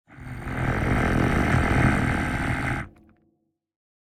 Minecraft Version Minecraft Version snapshot Latest Release | Latest Snapshot snapshot / assets / minecraft / sounds / mob / warden / angry_3.ogg Compare With Compare With Latest Release | Latest Snapshot
angry_3.ogg